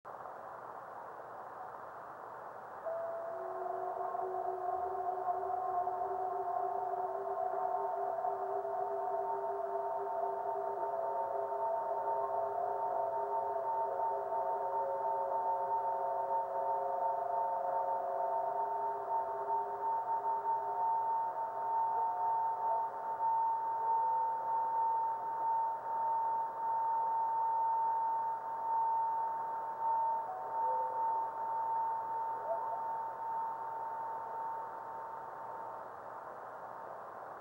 Below:  Radio spectrogram of the time of the meteor.  61.250 MHz reception above white line, 83.250 MHz below white line.